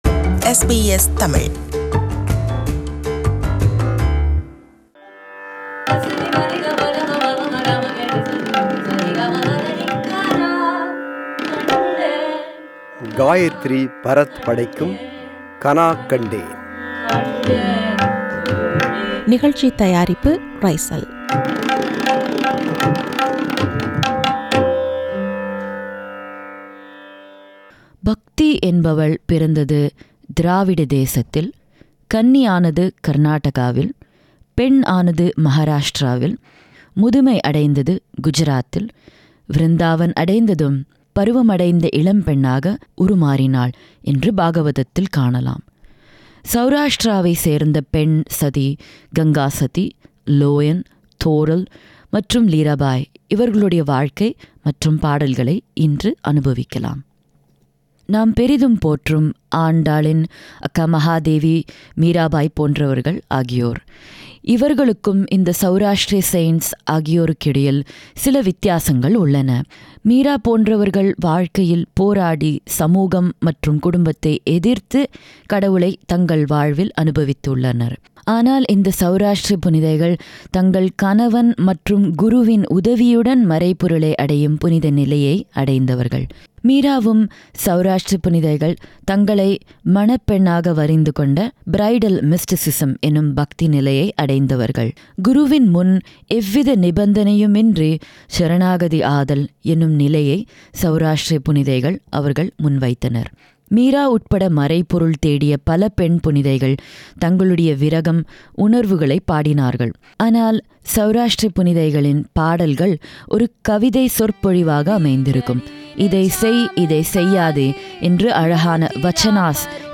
தெய்வீகத்தை, மறைபொருளைத் தேடிய ஒன்பது பெண்களின் வாழ்க்கையையும், அவர்களின் பாடல்களையும் (mystic women) பாடி, விவரிக்கும் தொடர் இது.
Harmonium
Tabla
Mridangam
Tanpura
Studio: SBS